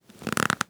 archer_bow_pull.wav